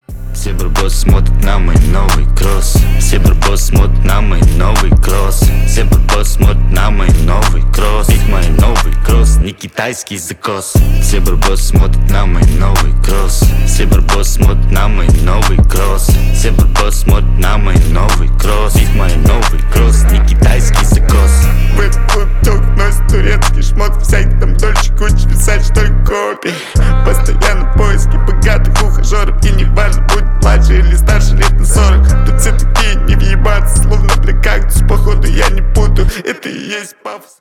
Ненормативная лексика!
Рэп и Хип Хоп
громкие